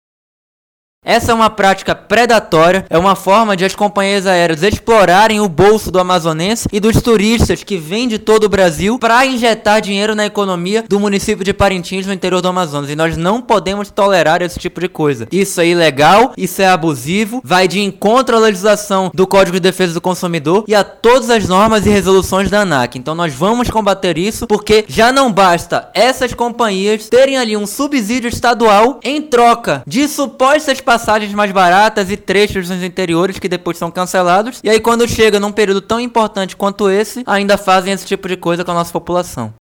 O deputado federal pelo Amazonas, Amom Mandel, criticou duramente os preços “extorsivos” das passagens aéreas praticados no período do festival e apresentou alguns exemplos.
Sonora-Amom-Mandel.mp3